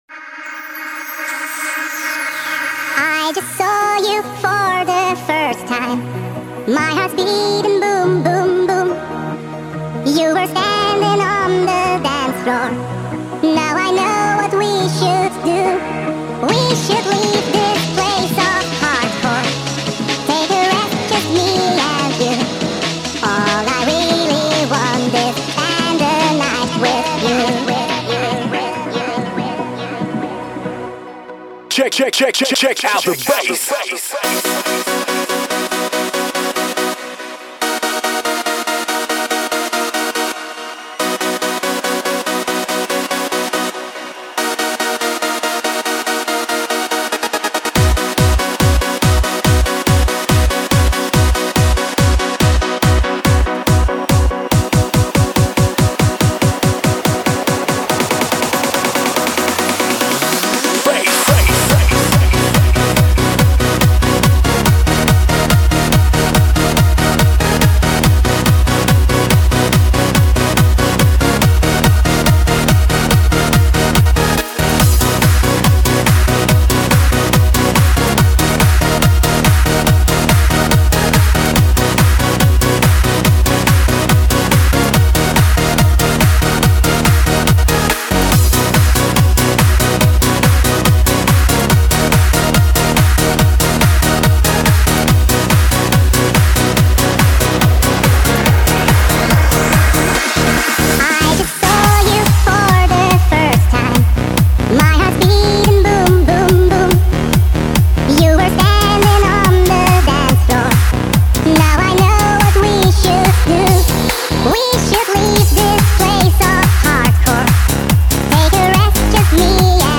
Жанр:Новогодний/Позитивный/Club/Dance